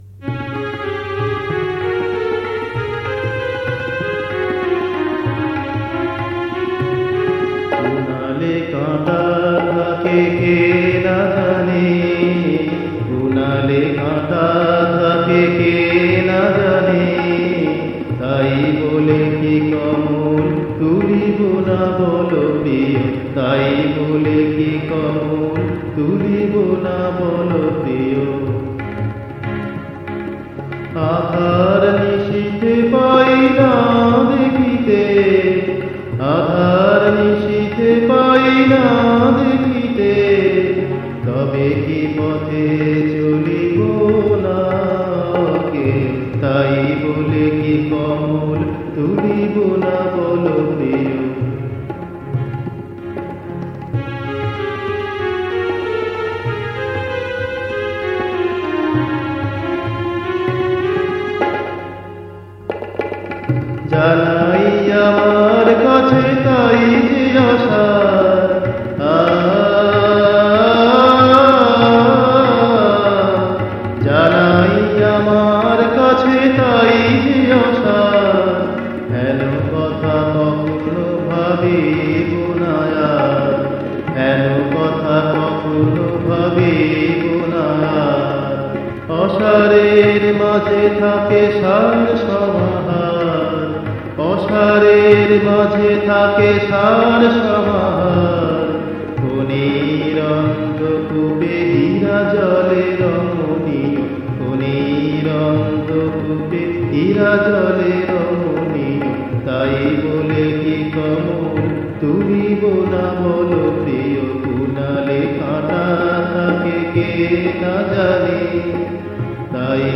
Music Kaharva